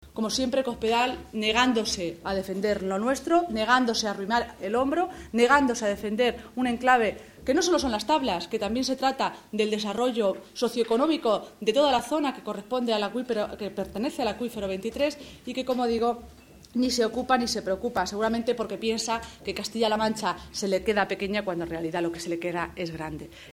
La senadora socialista Cristina Maestre critica que la secretaria general del PP lleve ausente de nuestra Región veinte días, “lo que demuestra, una vez más que no le interesa Castilla-La Mancha”
Cortes de audio de la rueda de prensa